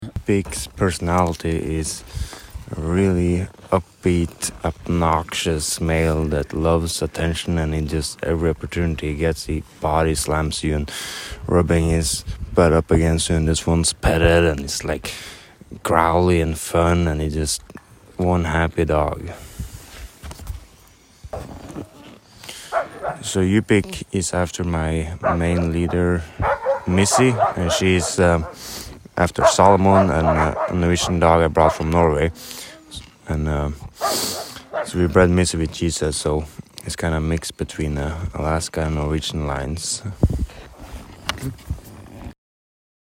Current Location: Takotna, Alaska